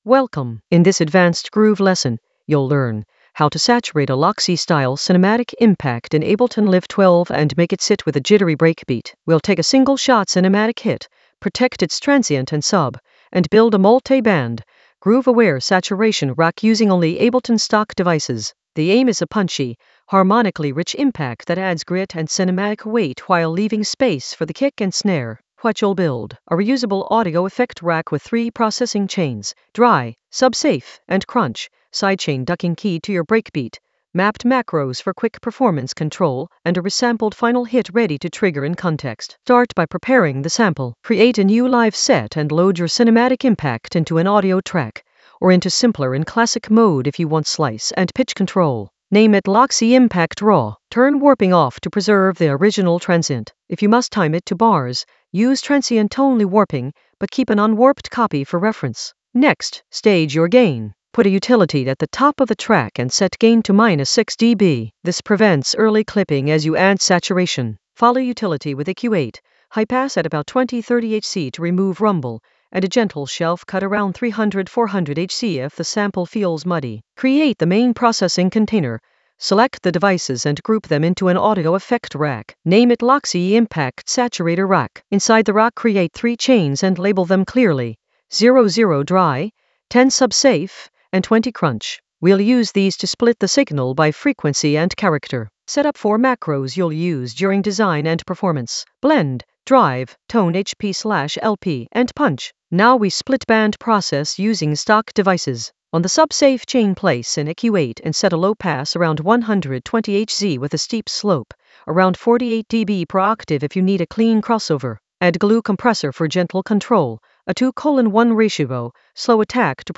An AI-generated advanced Ableton lesson focused on Saturate a Loxy cinematic impact in Ableton Live 12 for breakbeat science in the Groove area of drum and bass production.
Narrated lesson audio
The voice track includes the tutorial plus extra teacher commentary.